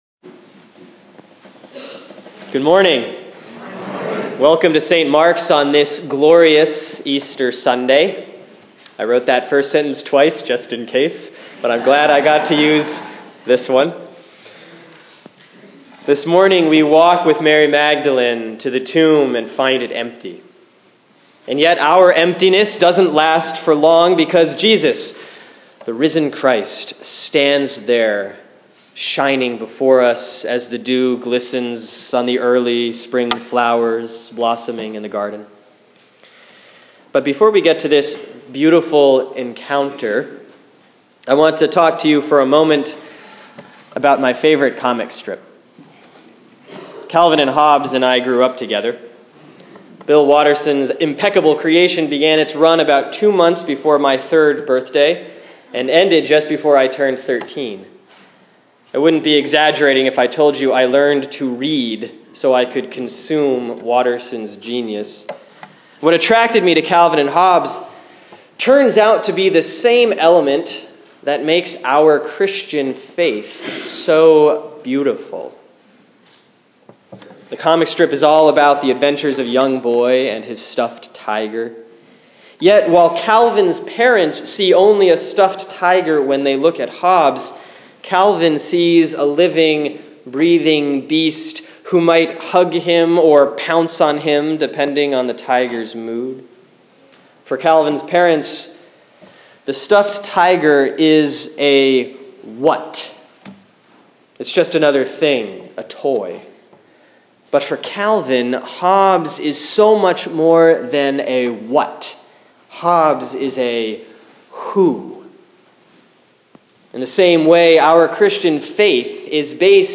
Sermon for Sunday, April 5, 2015 || Easter Sunday, Year B; John 20:1-18